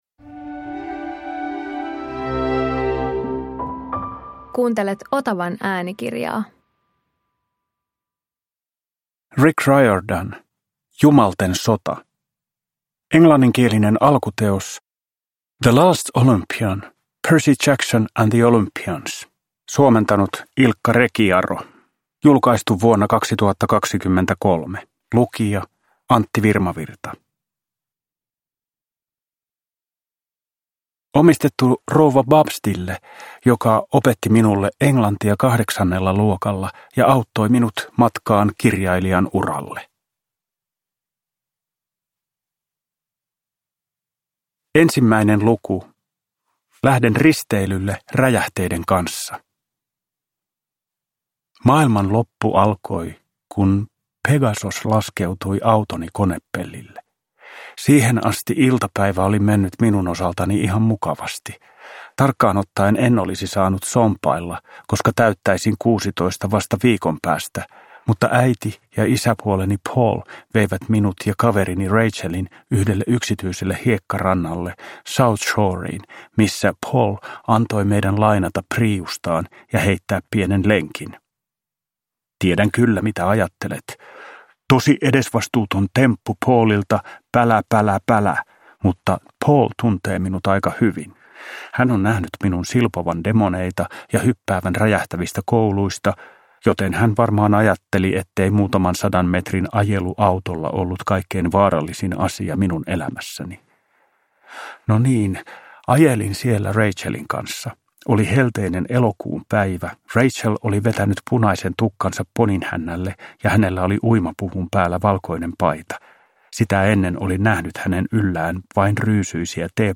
Jumalten sota – Ljudbok – Laddas ner